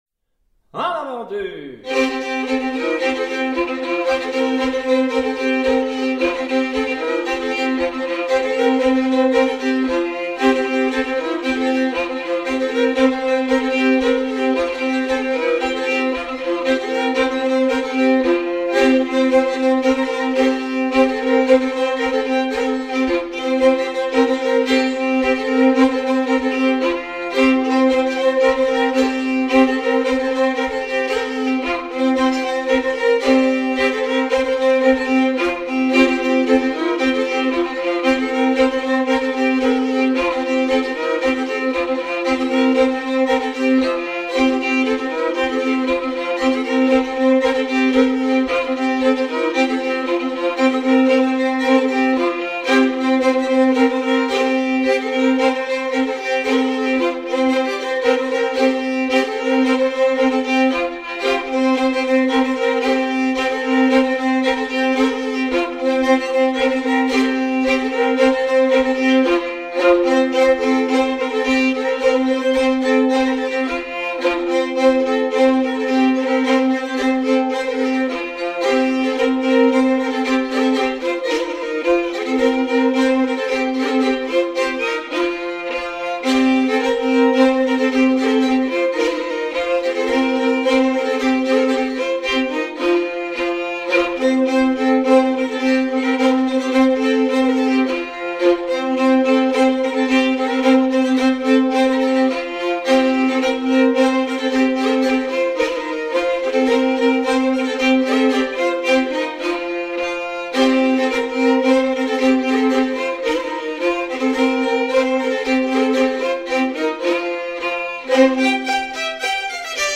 marches enregistrées en 1956
danse : branle : avant-deux
à marcher